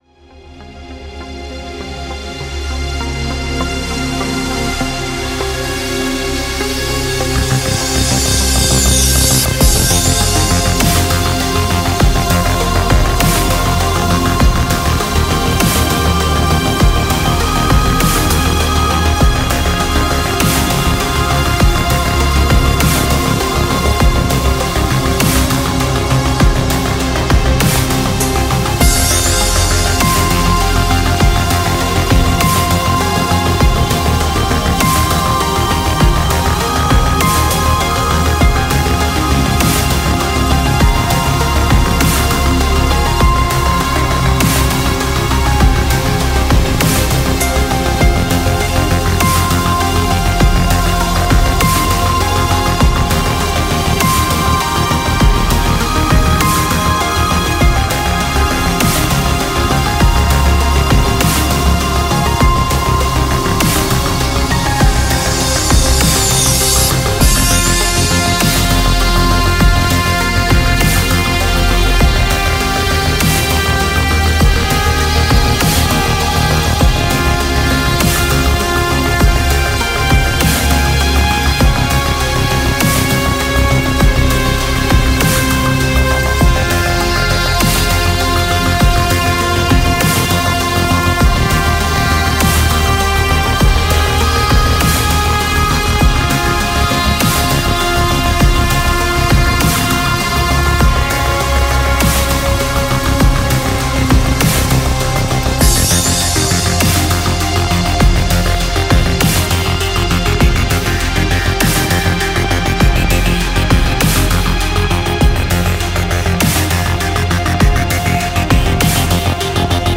BPM25
Comments[DARK AMBIENT]